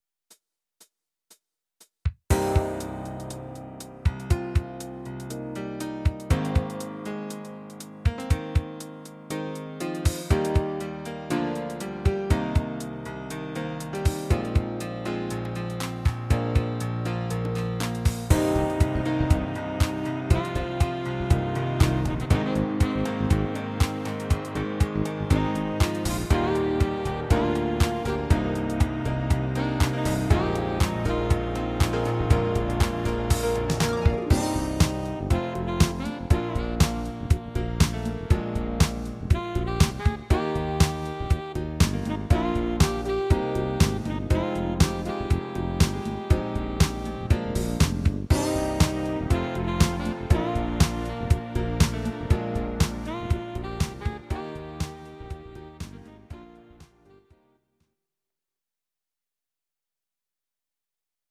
These are MP3 versions of our MIDI file catalogue.
Please note: no vocals and no karaoke included.
Your-Mix: Disco (724)